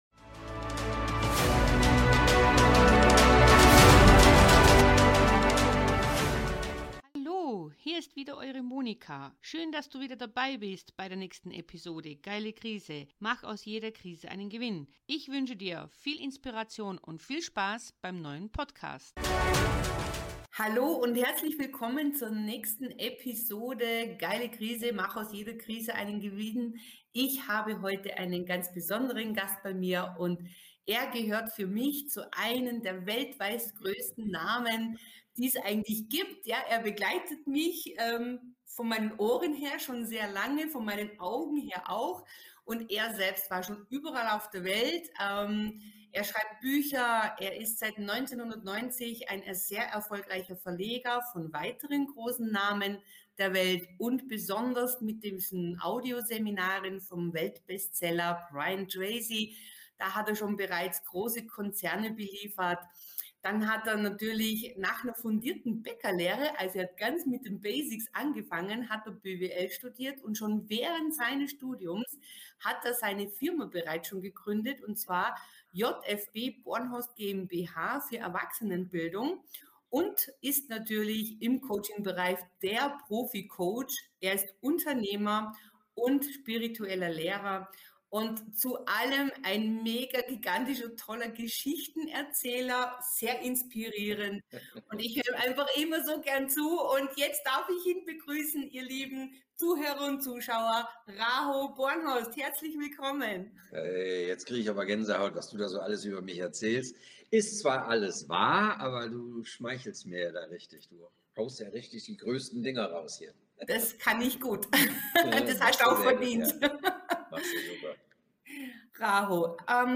In diesem Interview spricht er darüber, warum auch wenn man alles hat, Geld, Erfolg (Haus, Auto, Firma..) und dennoch eine Leere spürt und unglücklich ist. Er erzählt von seinen spirituellen Reisen und seinem Unternehmertum.